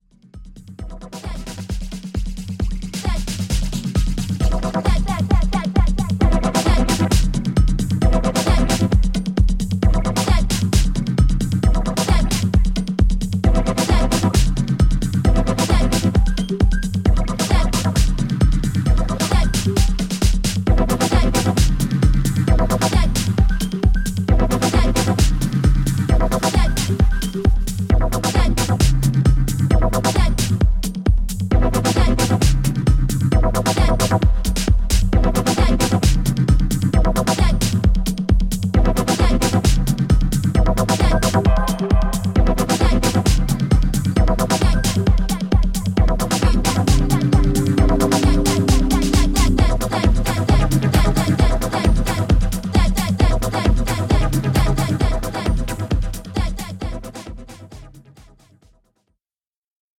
ファンキーなA面、空間を生かしたアンビエント・ダンスホール？